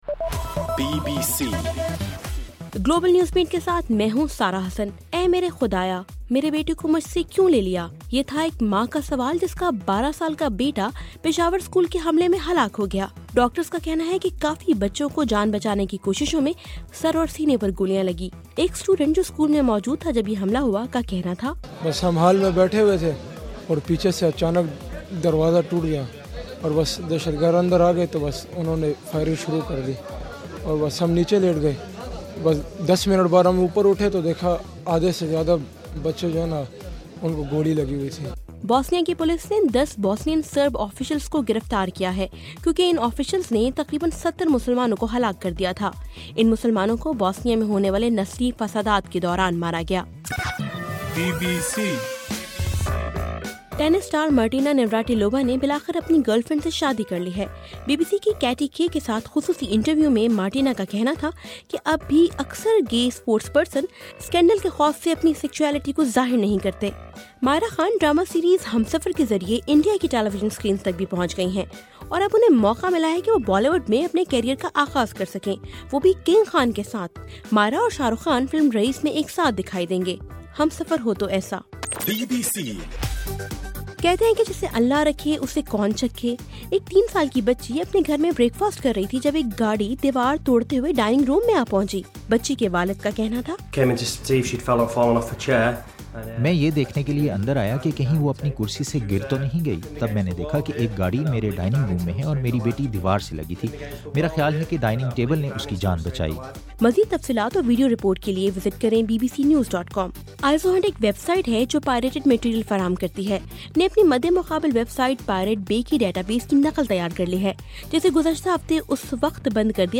دسمبر 17: صبح 1 بجے کا گلوبل نیوز بیٹ بُلیٹن